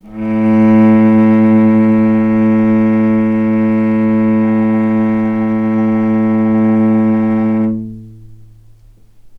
vc-A2-mf.AIF